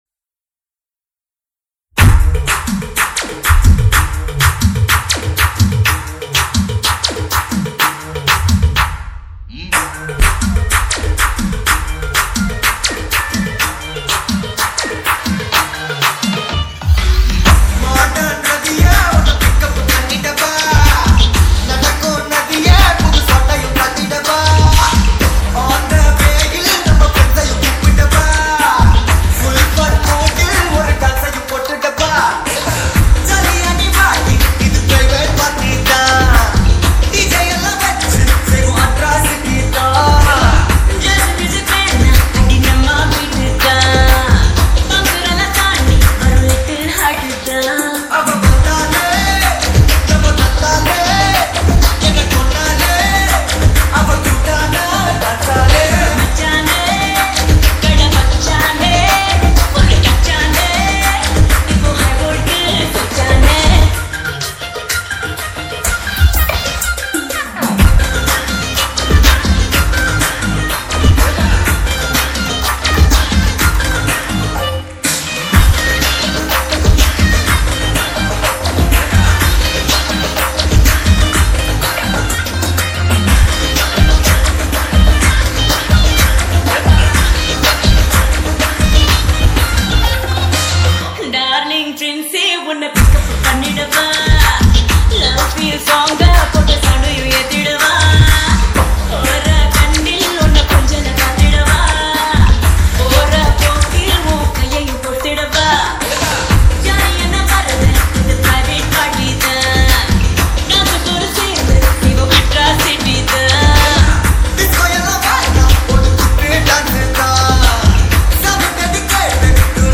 Tamil 8D Songs